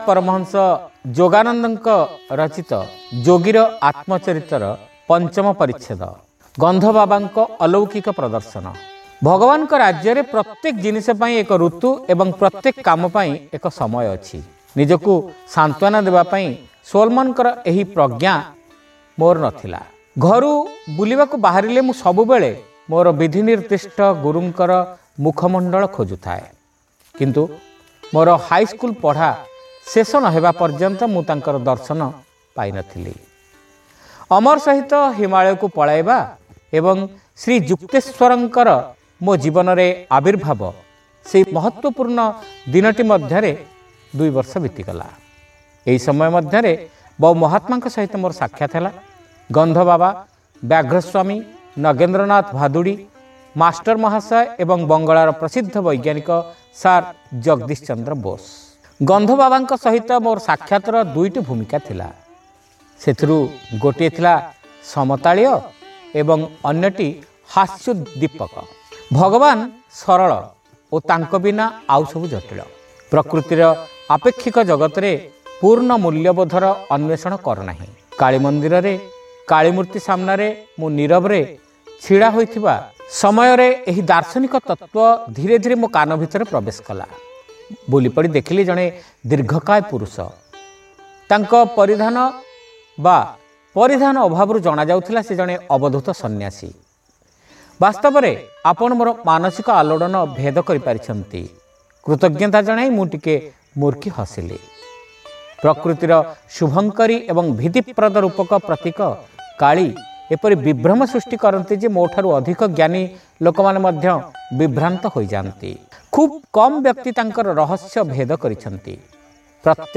ଶ୍ରାବ୍ୟ ଗଳ୍ପ : ଗନ୍ଧ ବାବାଙ୍କ ଅଲୌକିକ ପ୍ରଦର୍ଶନ-ଯୋଗୀର ଆତ୍ମଚରିତ